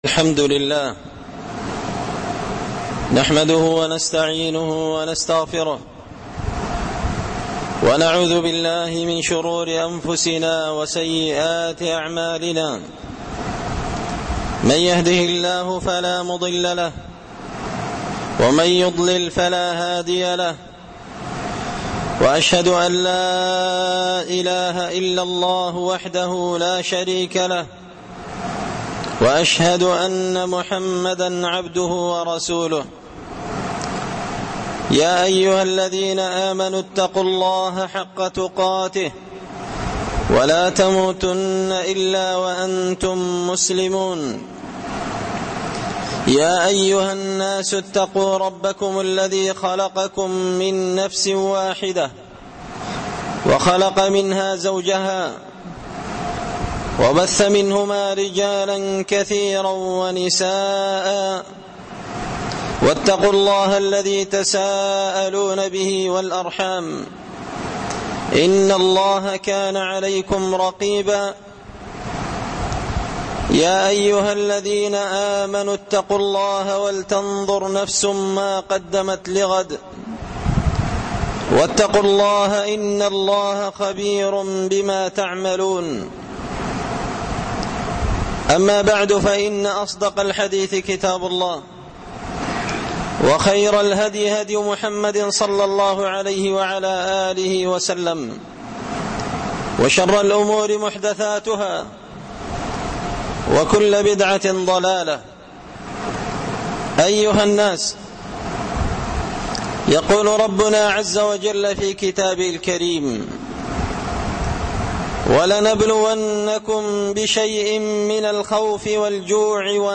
خطبة جمعة بعنوان – إتحاف الأخيار بأسباب وعلاج غلاء الأسعار
دار الحديث بمسجد الفرقان ـ قشن ـ المهرة ـ اليمن